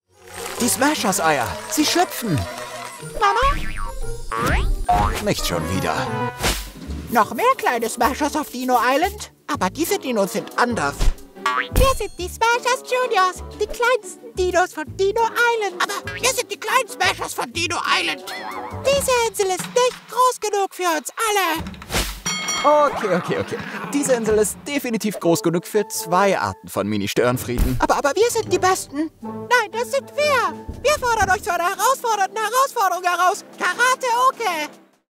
Natural, Reliable, Friendly, Commercial, Warm